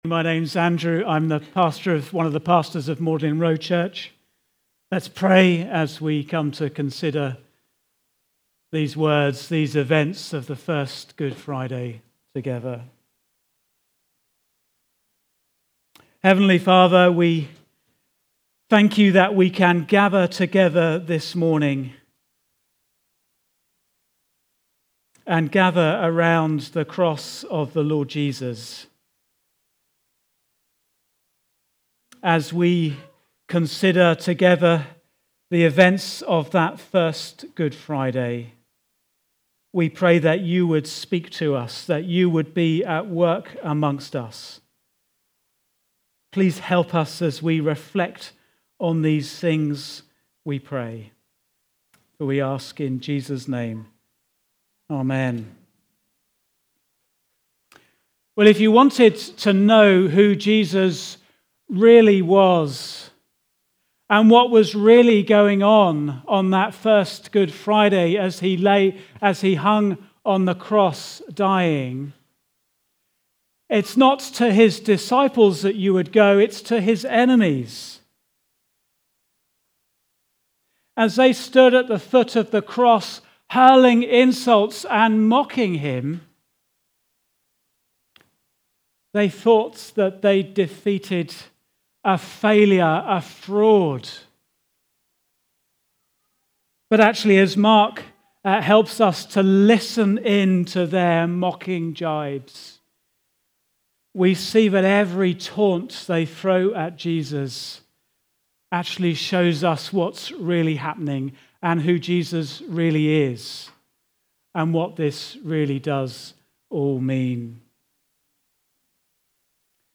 Preaching
Good Friday (Mark 15:1-39) Recorded at Woodstock Road Baptist Church on 03 April 2026.